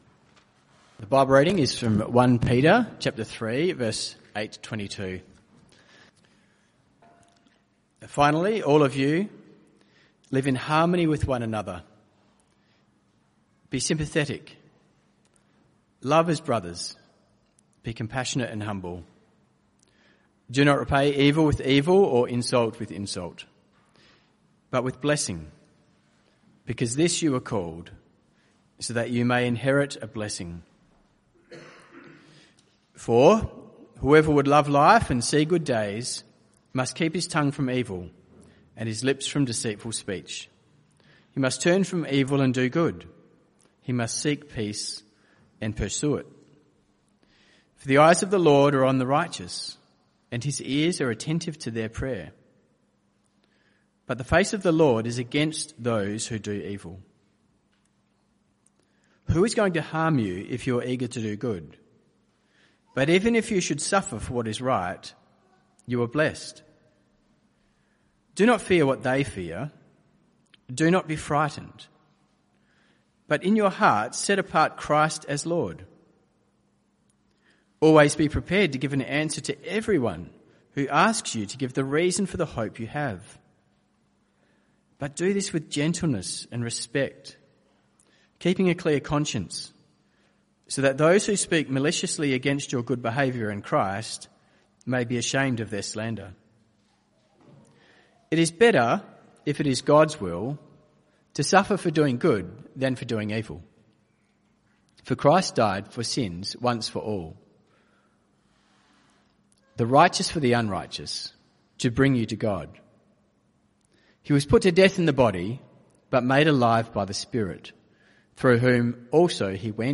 CBC Service: 19 May 2024 Series
Type: Sermons